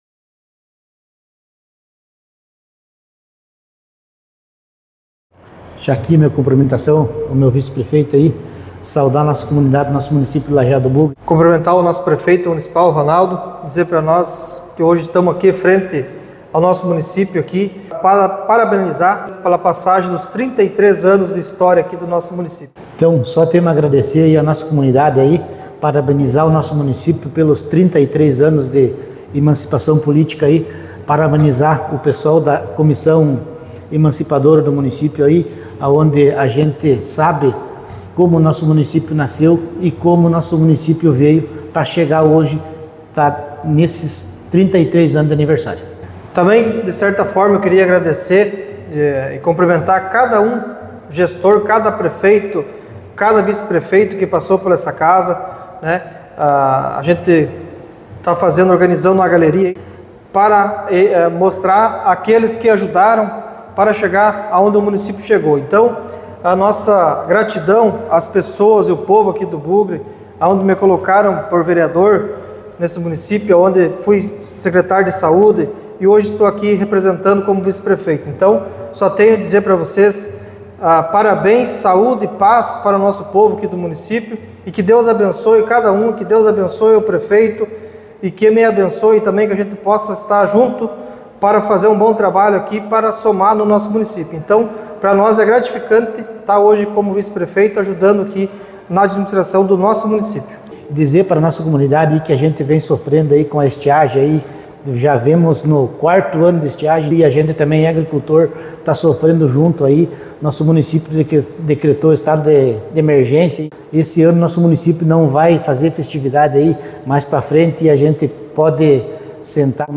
O prefeito Ronaldo Machado e o vice-prefeito Maico de Lima compartilham suas reflexões sobre essa data significativa.